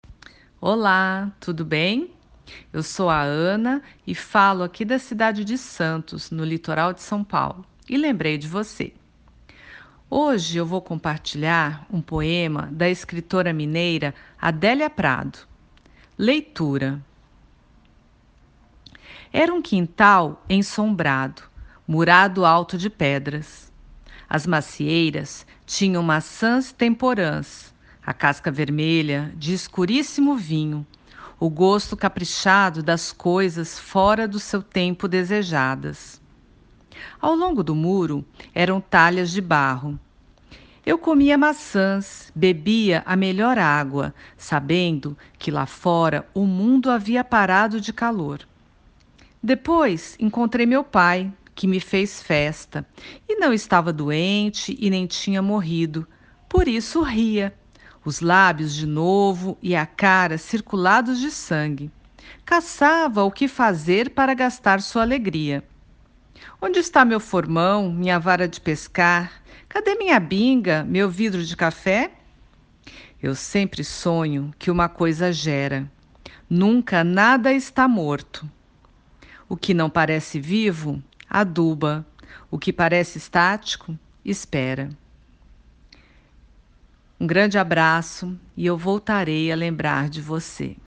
Poema Português
Leitura